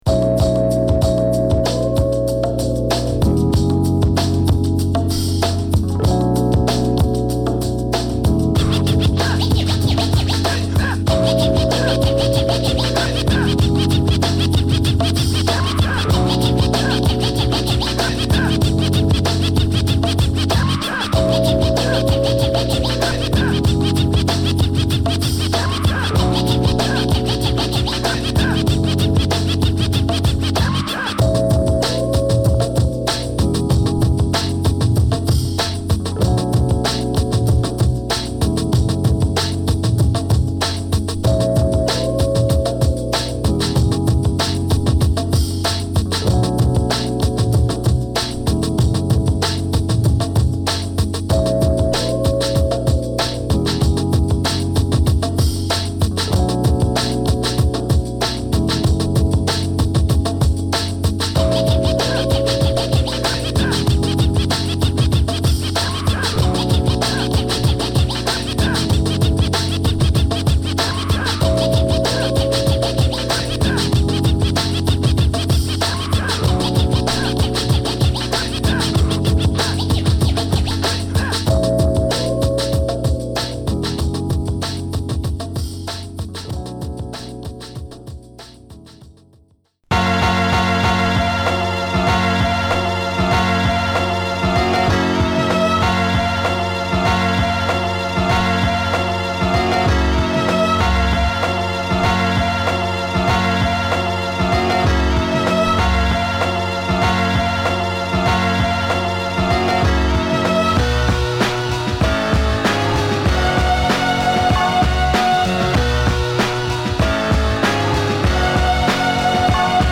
ラフなループ＆ビート打ちのオリジナル・ヴァイブスを纏ったトラックを満載！